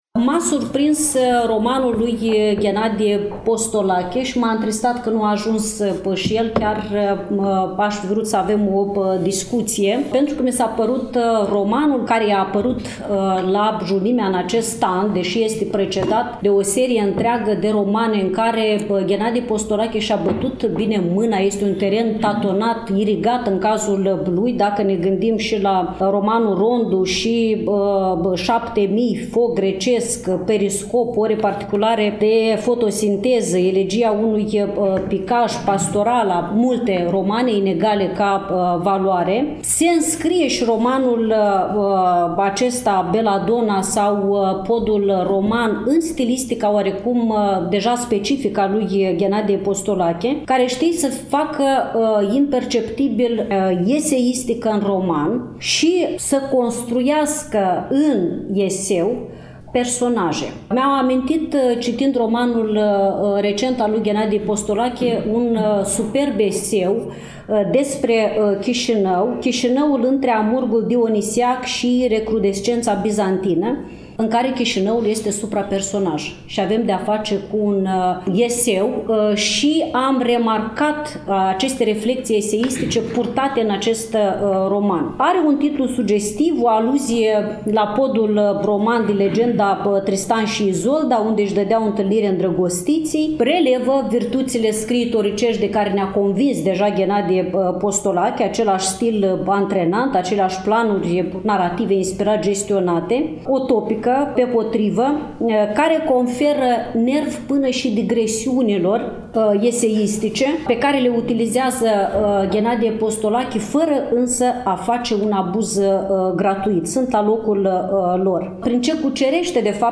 Cărțile au fost prezentate, la Iași, în ziua de joi, 21 noiembrie 2024, începând cu ora 14, în incinta sediului Editurii Junimea din Parcul Copou